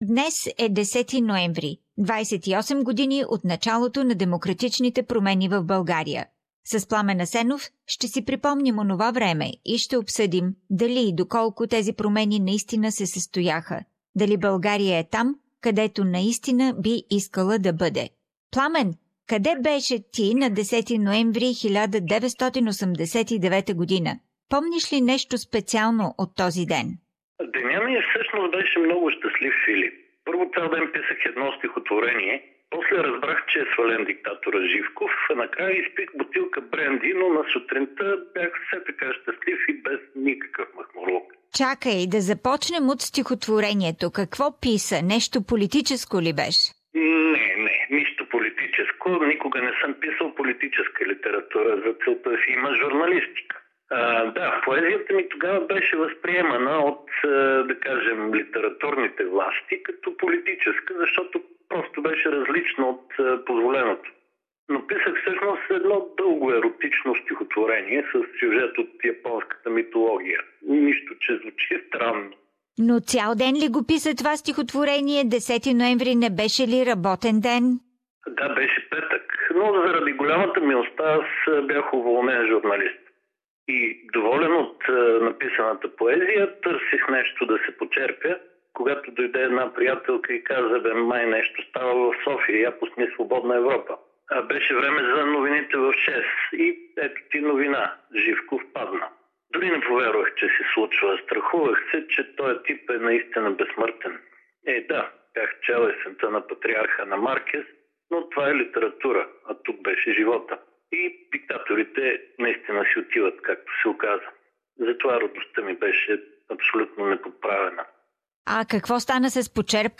Political commentary